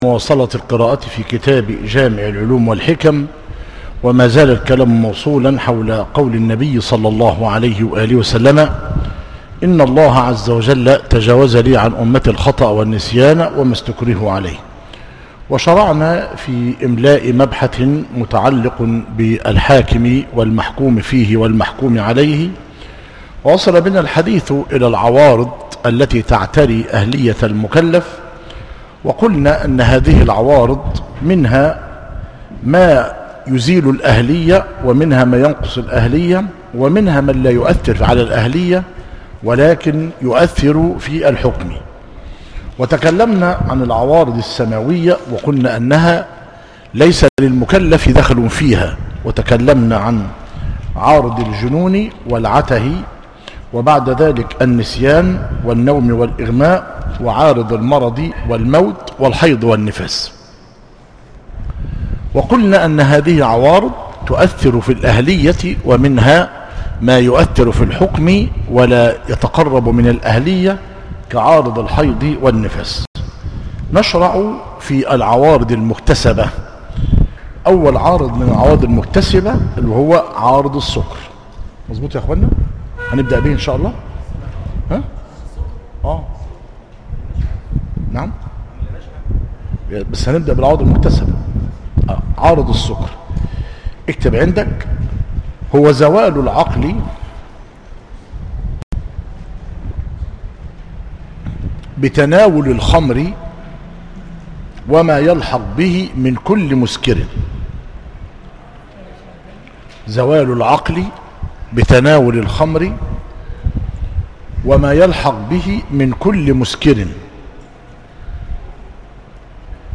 جامع العلوم والحكم- مسجد التقوى - قرية الجعافرة - مركز شبين القناطر - قليوبية - المحاضرة الخامسة والثلاثون بعد المئة - بتاريخ 21- ذو القعدة - 1438 هجريا الموافق 13 - أغسطس- 2017 م